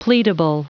Prononciation du mot pleadable en anglais (fichier audio)
Prononciation du mot : pleadable